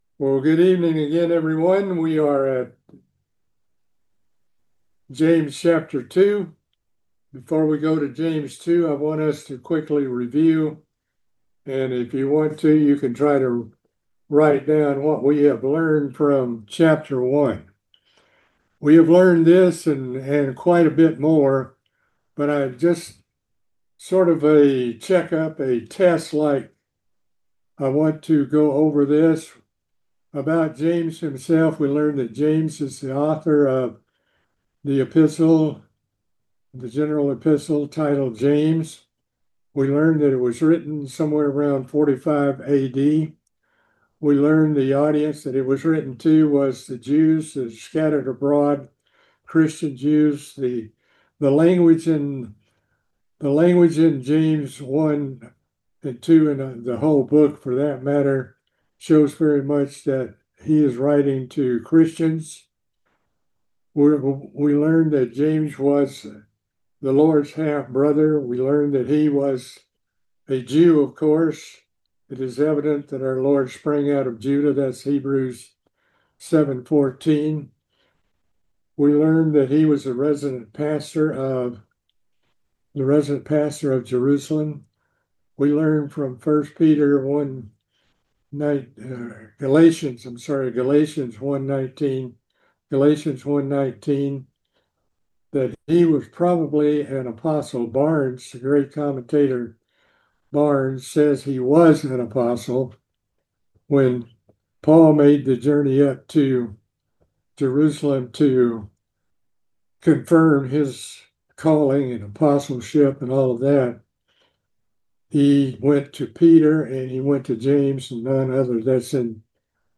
We continue our bible study of the General Epistles.